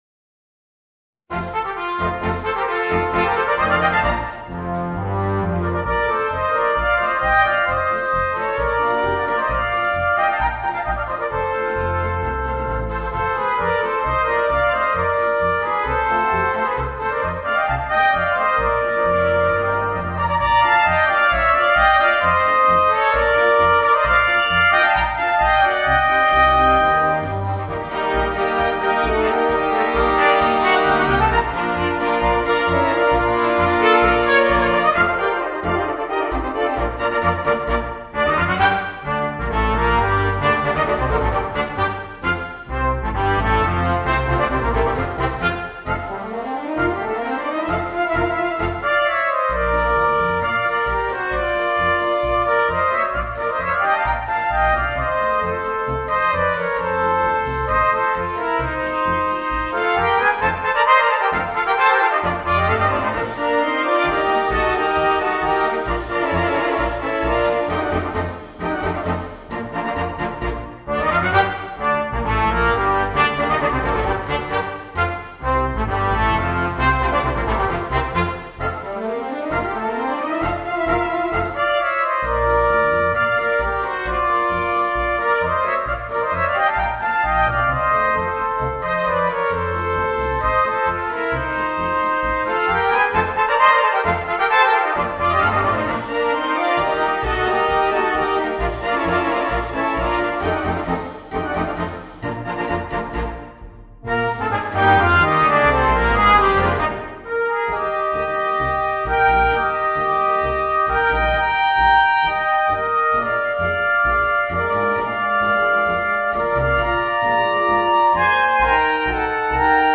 Voicing: 2 Euphoniums and Brass Band